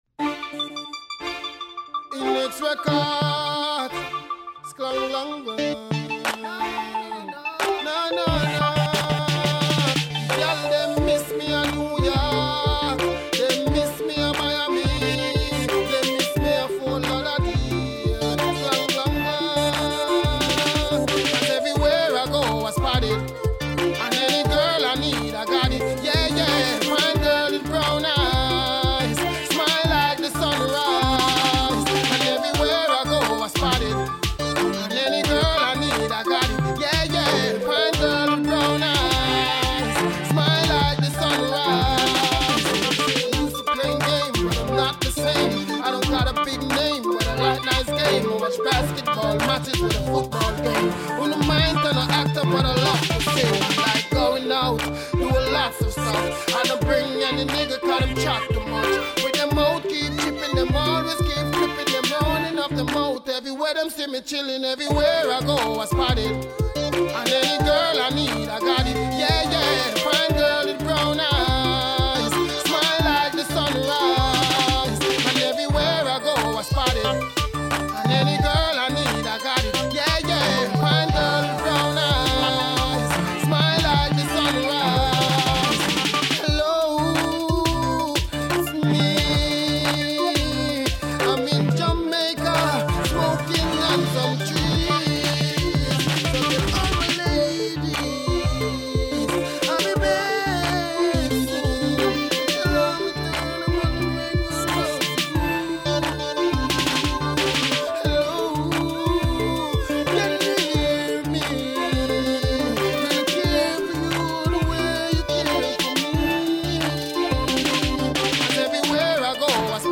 rap , RnB , soul